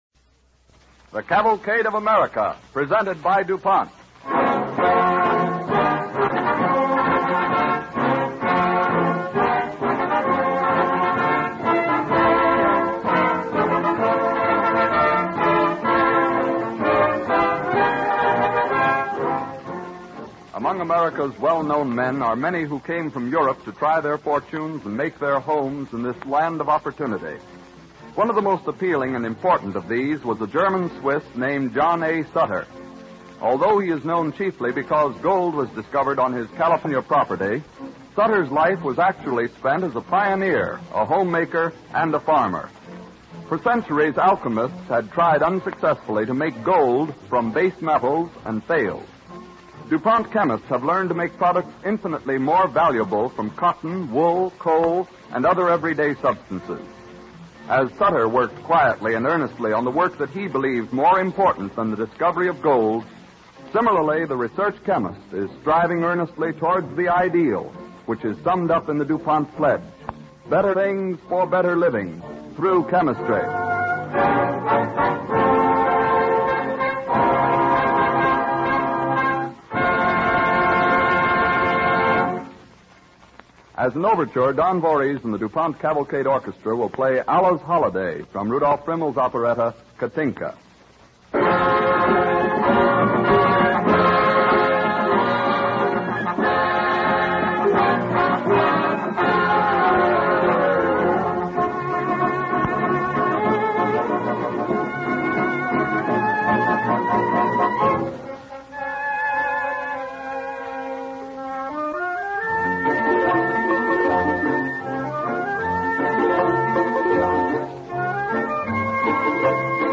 Cavalcade of America Radio Program